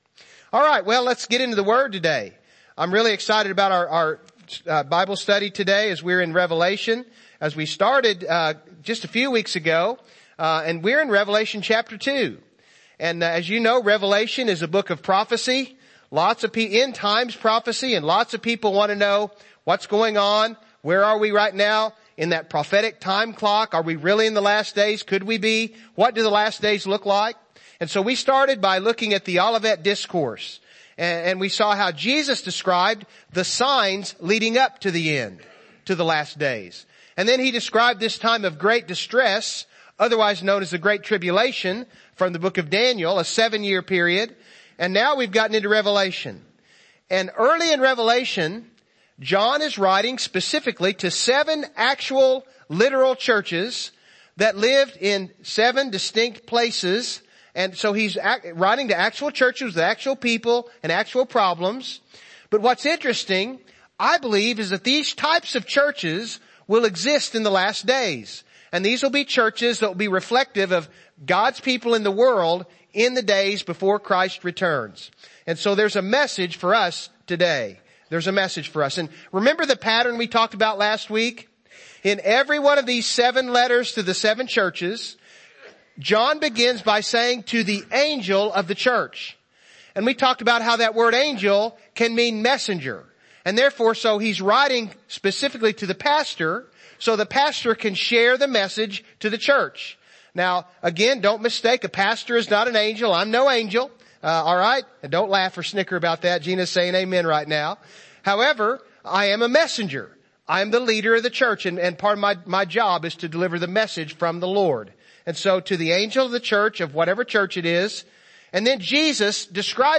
In the End Service Type: Morning Service « In the End